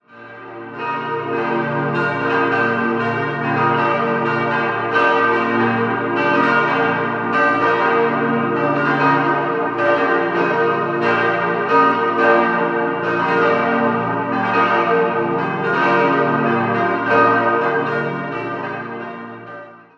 4-stimmiges Salve-Regina-Geläute: b°-d'-f'-g'
Christkönigsglocke
Marienglocke
Johannusglocke
Donatusglocke g'+1 780 kg 1.068 mm 1928 Mabilon, Saarburg